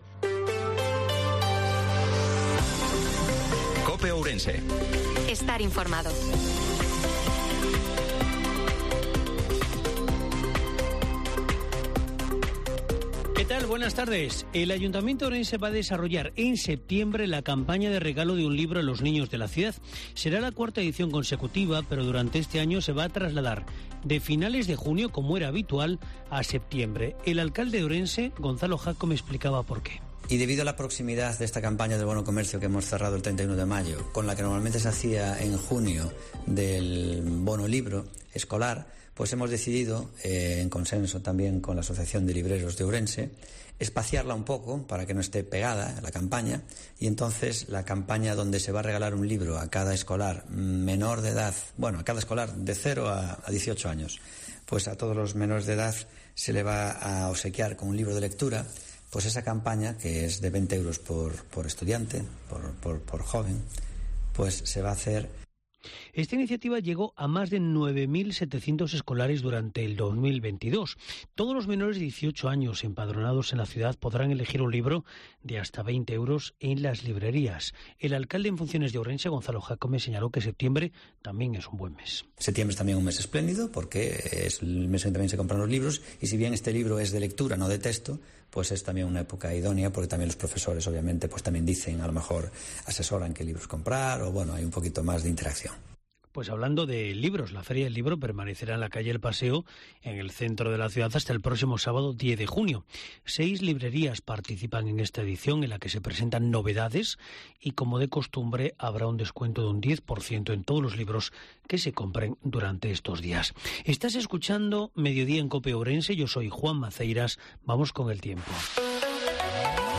INFORMATIVO MEDIODIA COPE OURENSE-08/06/2023